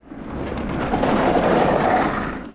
Frenada fuerte de un coche 1
coche
frenar
Sonidos: Transportes